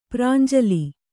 ♪ prānjali